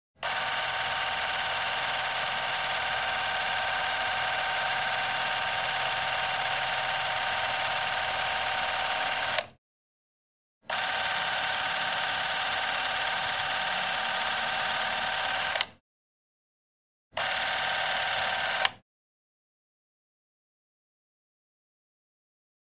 8 mm Newsreel Motion Picture Camera On, Run, And Off, With Clicks And Clatters.